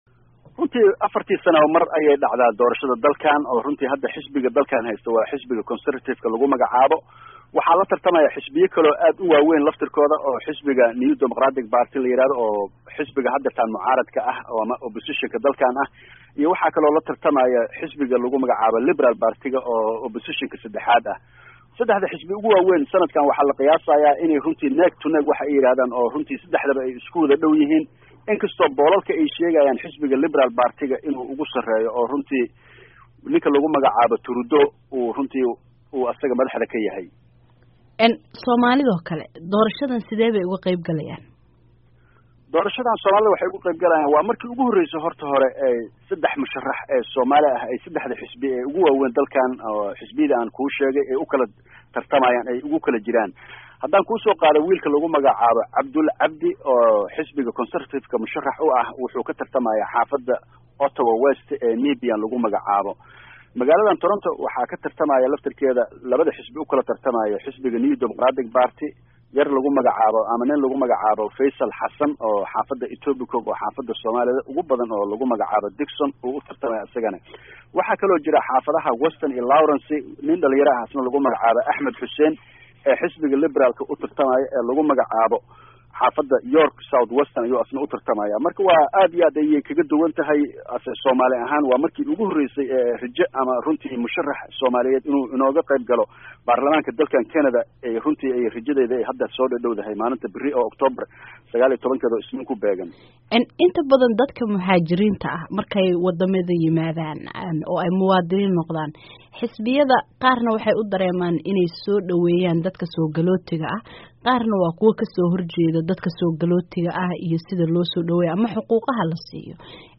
Wareysi la xiriira doorashada Canada